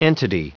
Prononciation du mot entity en anglais (fichier audio)
Prononciation du mot : entity